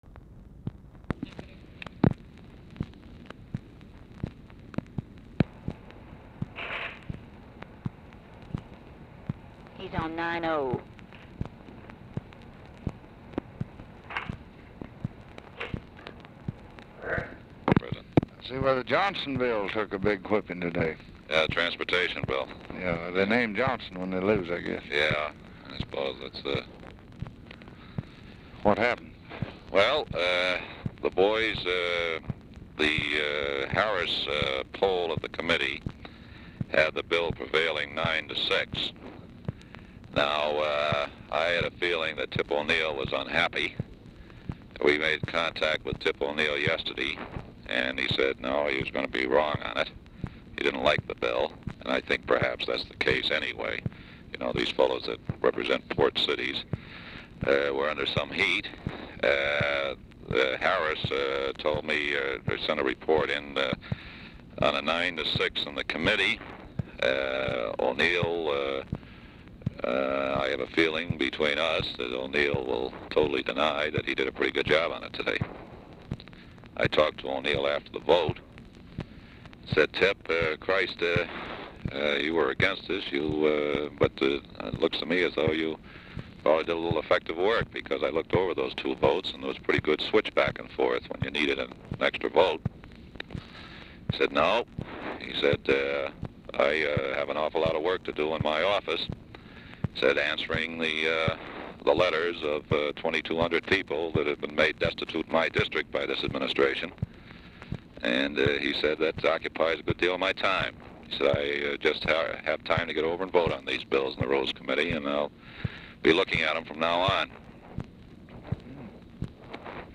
Format Dictation belt
Specific Item Type Telephone conversation